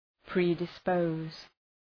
Προφορά
{,pri:dı’spəʋz}